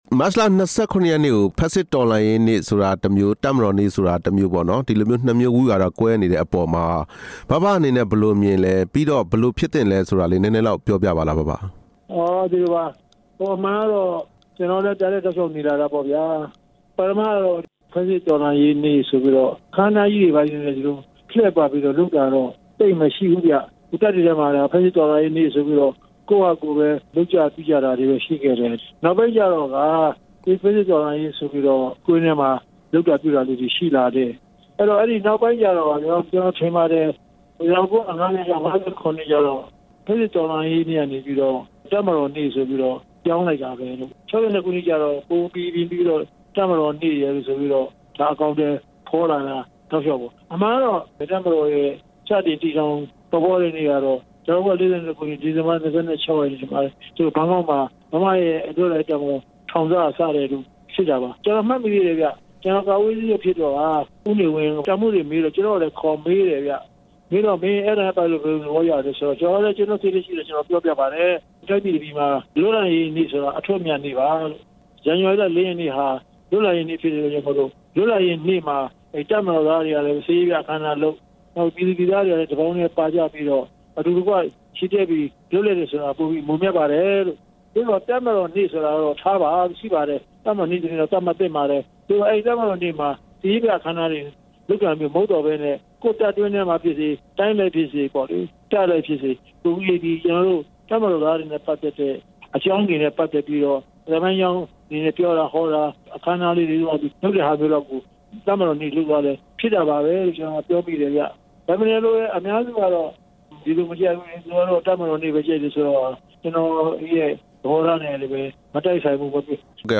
ဖက်ဆစ်တော်လှန်ရေးနေ့ NLD ခေါင်းဆောင် ဦးတင်ဦးနဲ့ မေးမြန်းချက်